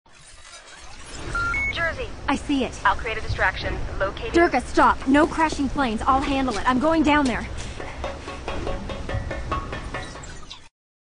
ILB_Crashing_planes.ogg.mp3